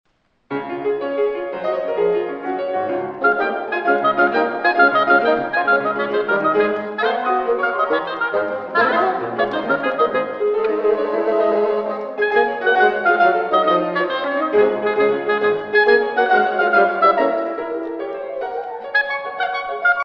(live)